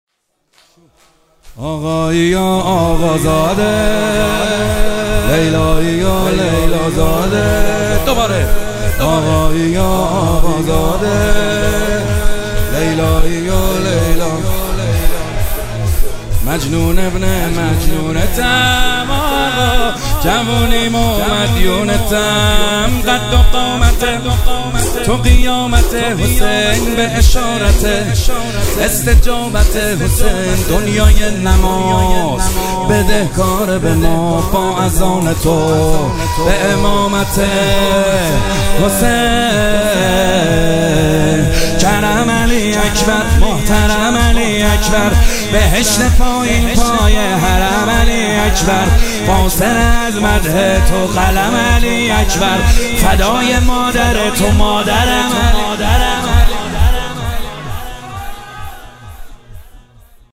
شب سوم فاطمیه دوم صوتی -شور - آقایی و آقا زاده - محمد حسین حدادیان